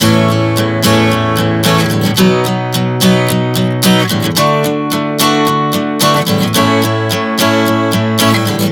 Prog 110 A-B-D-A.wav